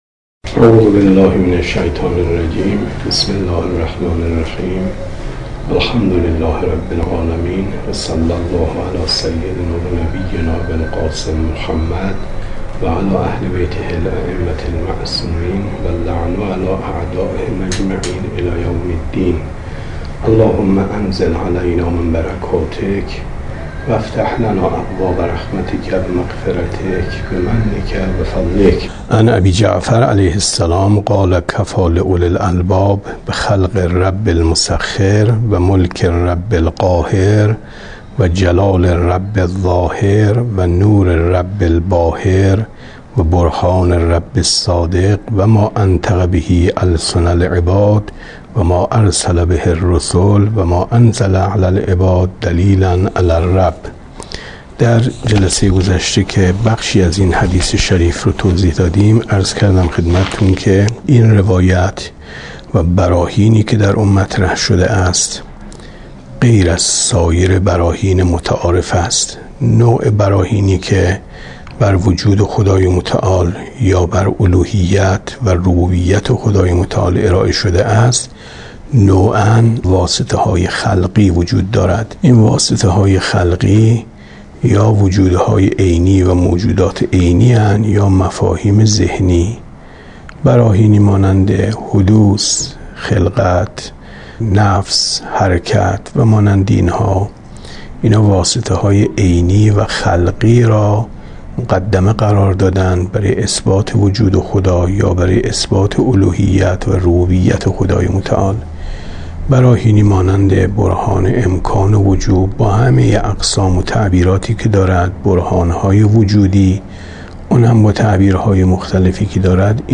کتاب توحید ـ درس 17 ـ 14/ 7/ 95